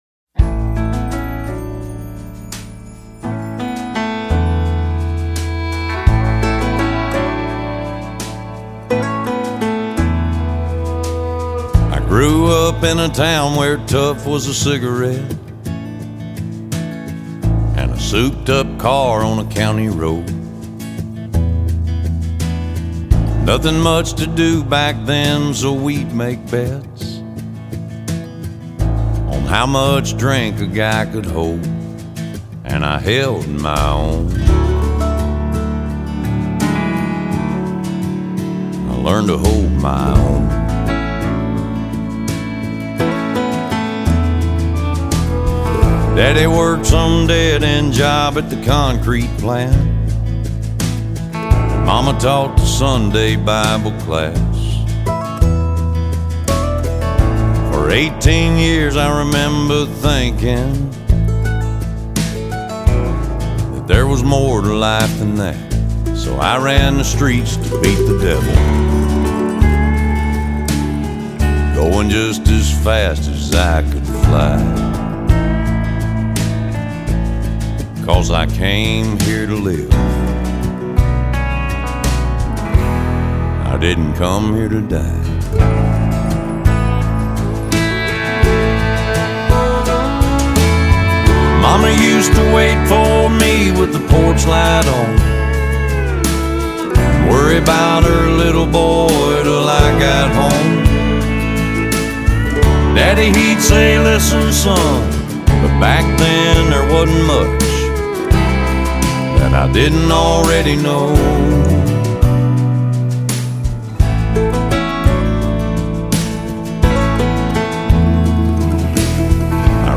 风格： Rock/Pop, Classical, Country
他的男中音相当迷人，音乐的多元化，嗓音的浑厚和成熟，一览无余的展示了出来。